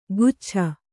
♪ guccha